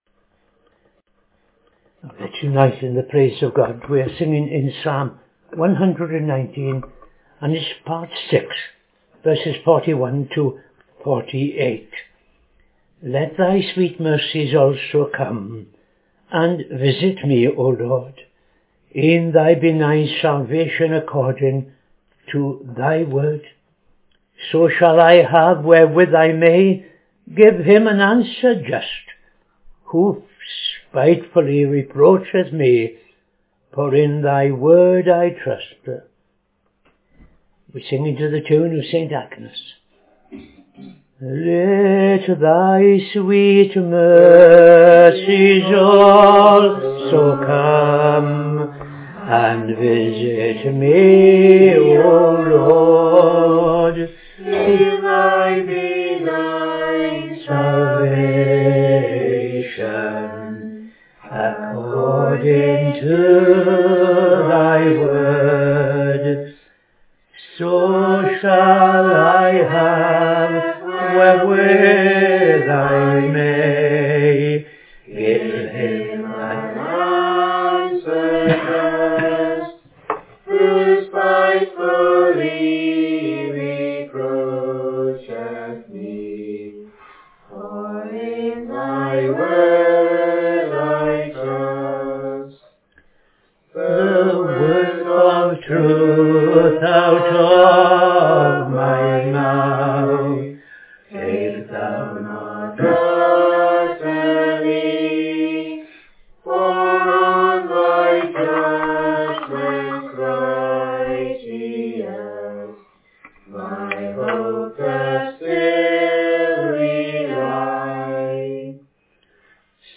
Evening Service - TFCChurch
5.00 pm Evening Service Opening Prayer and O.T. Reading II Chronicles 1:1-17